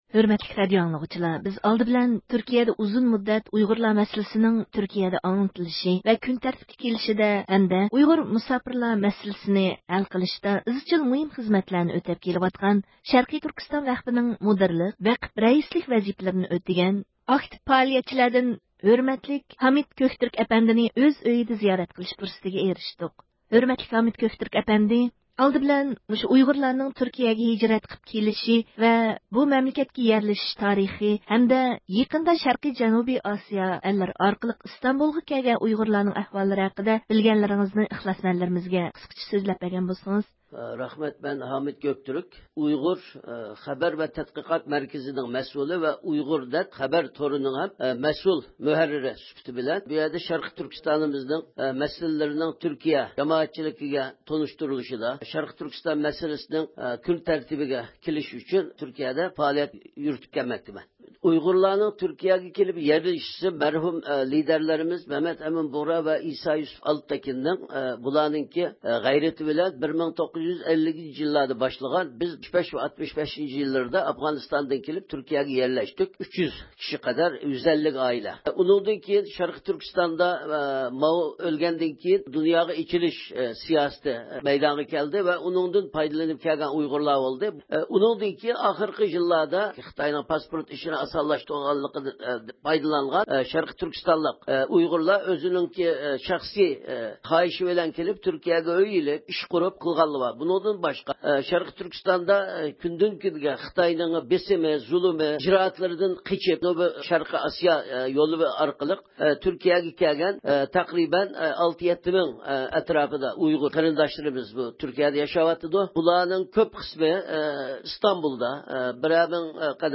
مەخسۇس سۆھبىتىنىڭ بىر قىسمىنى دىققىتىڭلارغا سۇنۇلدى: